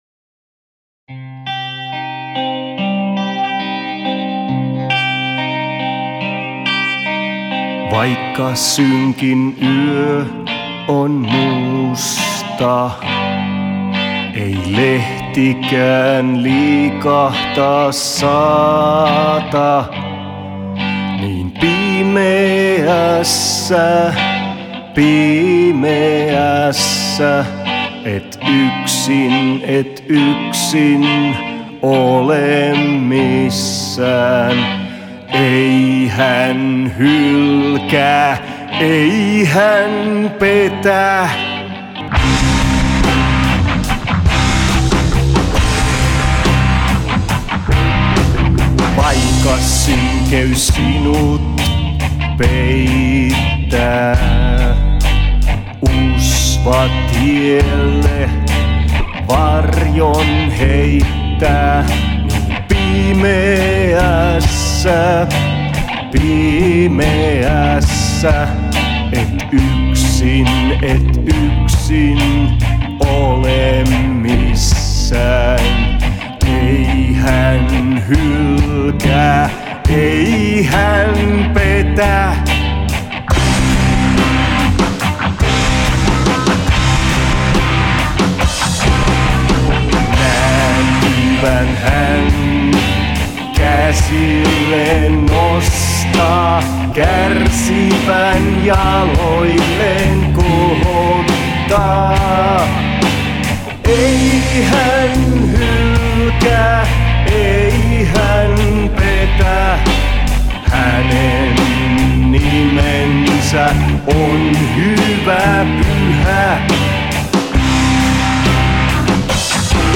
körttimetalliversio